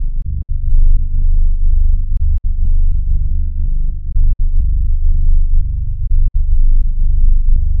• tech house bass samples - Dm - 123.wav
tech_house_bass_samples_-_Dm_-_123_ztP.wav